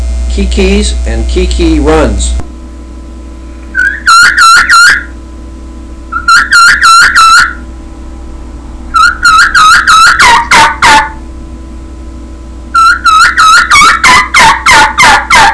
• QB Premium Green Latex, designed for crisp, raspy sounds and long life.
qbgrworldchampkeekee16.wav